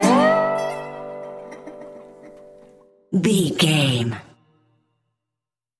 Ionian/Major
electric guitar
acoustic guitar
drums
ukulele
slack key guitar